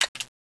reload_loop.wav